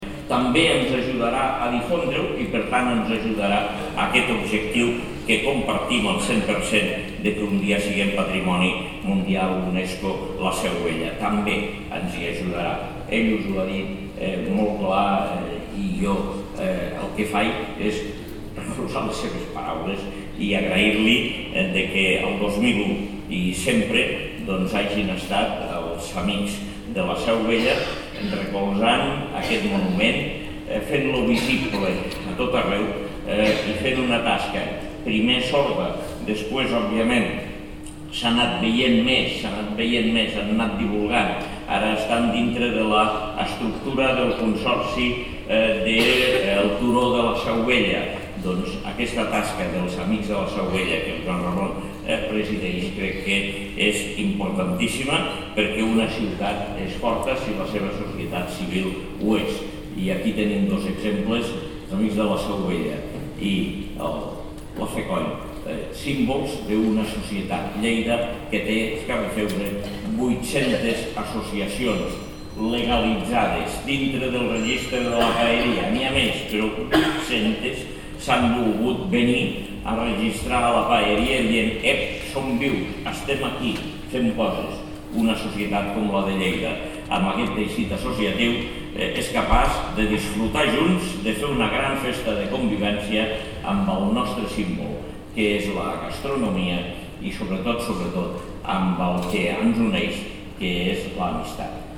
tall-de-veu-de-lalcalde-angel-ros-sobre-el-prego-de-laplec-del-caragol-de-lleida